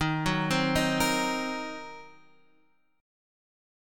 D#m6 chord